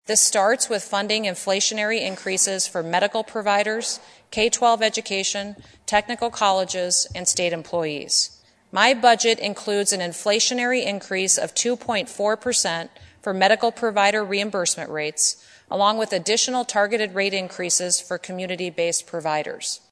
South Dakota Gov. Kristi Noem outlined her proposed 2022 State Budget during an address to state legislators today (Dec. 8, 2020) in Pierre. She listed three priorities, one of which is strengthening communities.